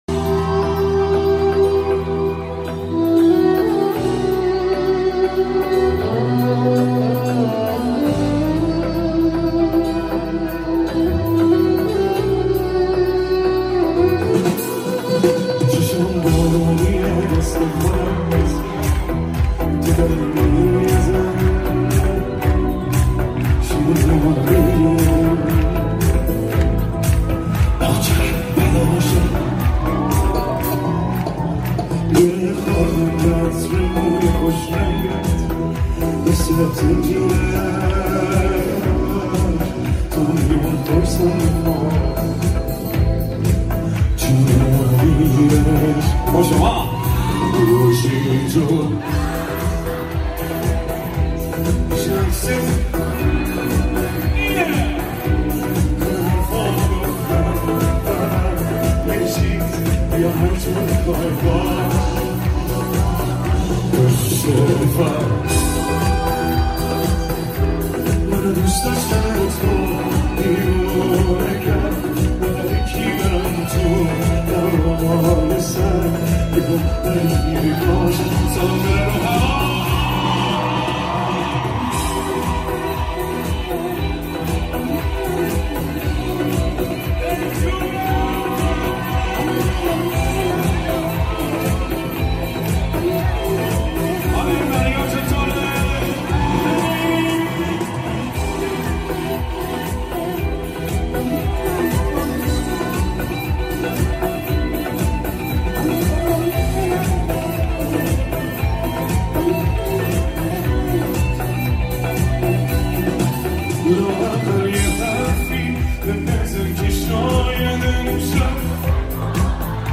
اجرای زنده در کنسرت